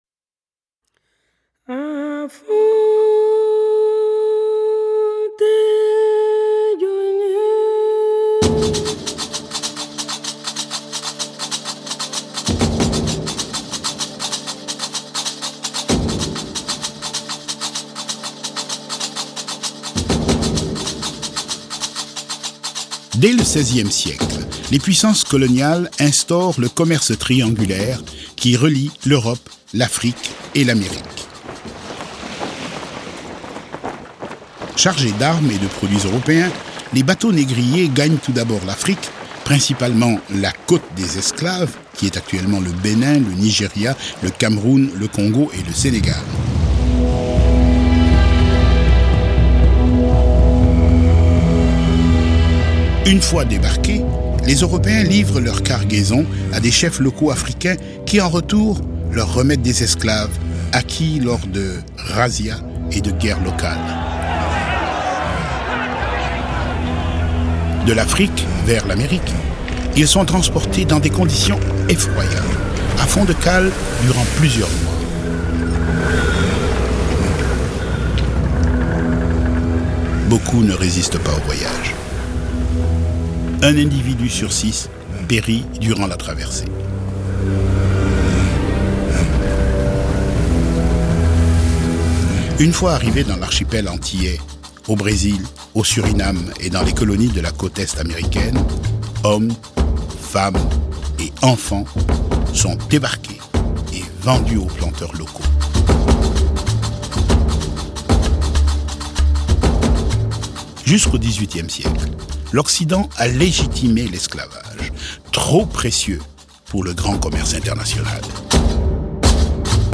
Narrateur